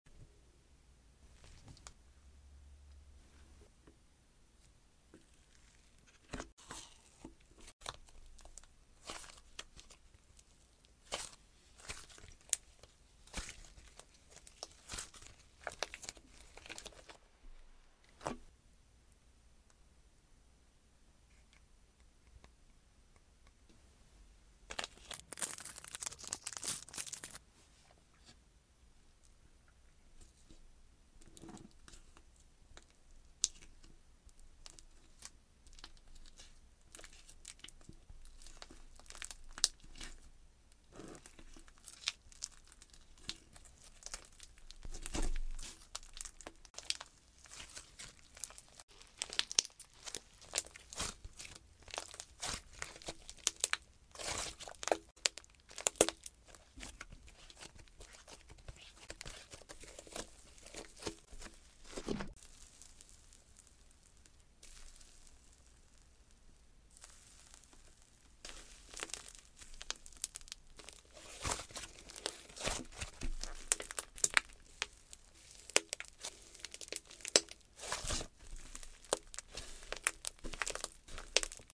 Slime ASMR review ❤ Lovesick sound effects free download